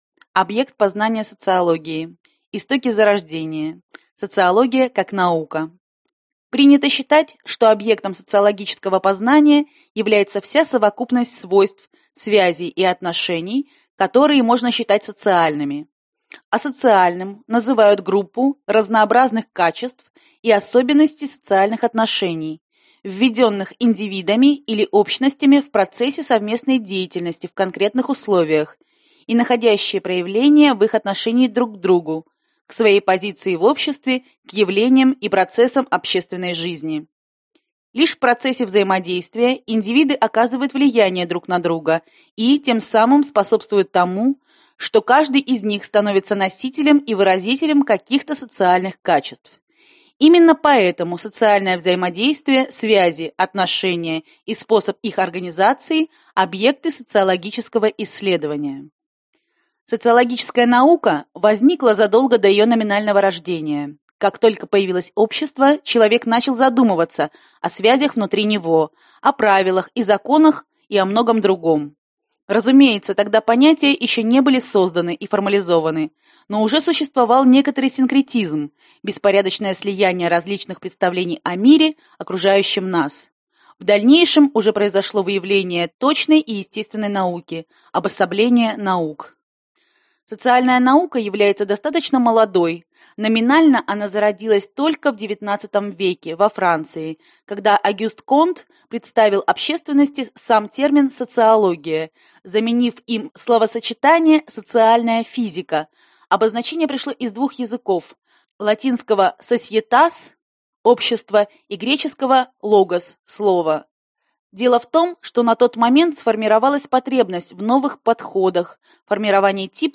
Аудиокнига Лекции по социологии | Библиотека аудиокниг